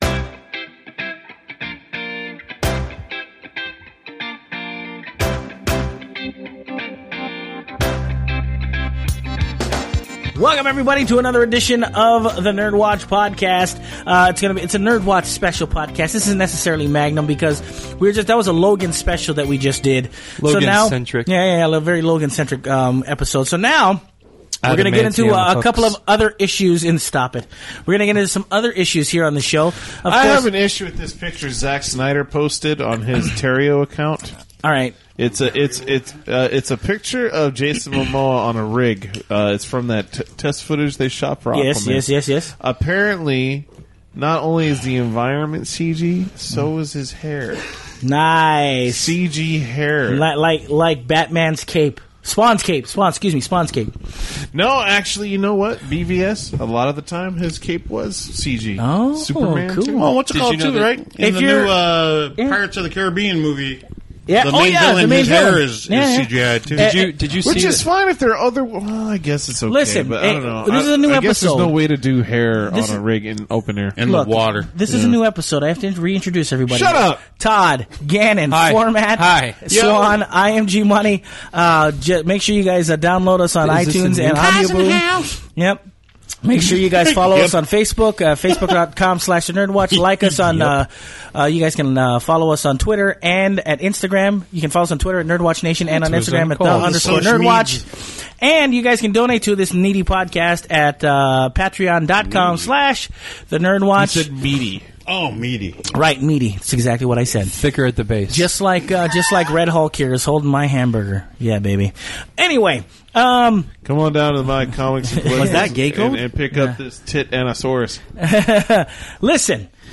The boys for the first time take a couple of on air calls about the Switch, the new Zelda launch title and the all new PS4 title, Horizon: Zero Dawn.